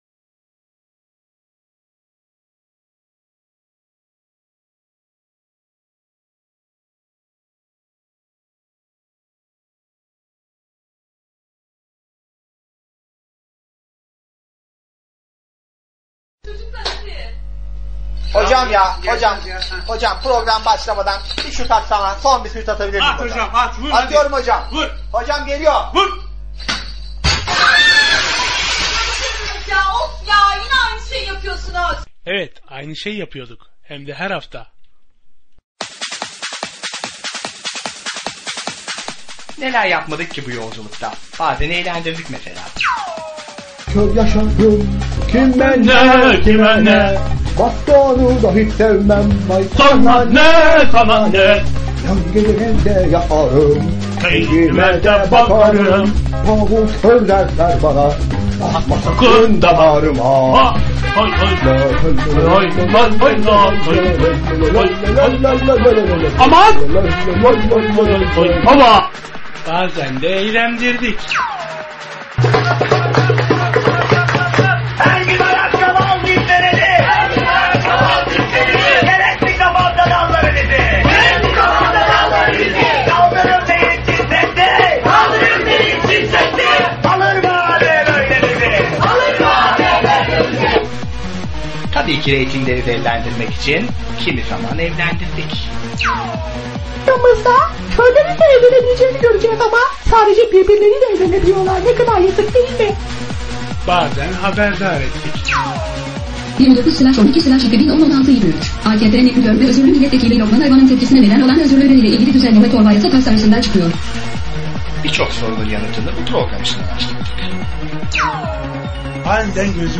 Bu akşam eğitimcilerimizle ve sizlerle Engelsiz Eğitimler'i konuşacağız. Sanatçı kamançası ile bizleri farklı diyarlara götürecek. Engelsiz Erişimli Saatler her Çarşamba 21:00-23:00 arası EGED Radyo’da.